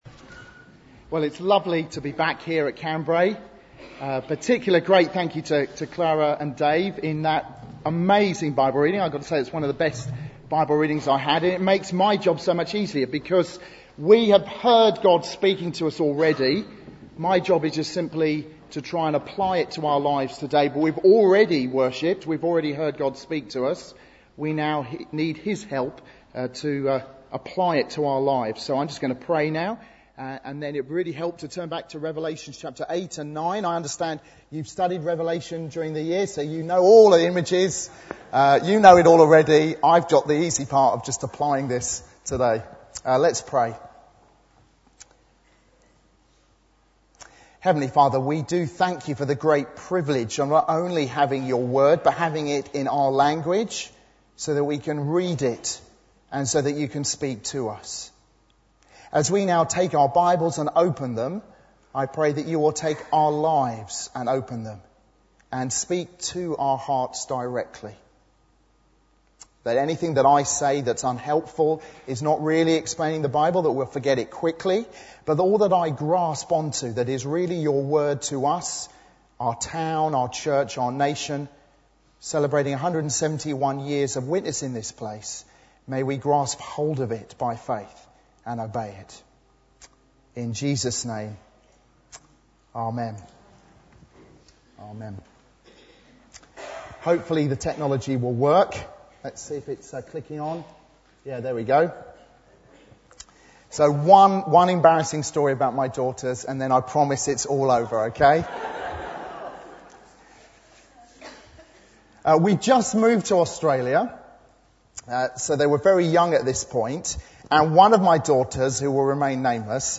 Church Anniversary 2014 – morning service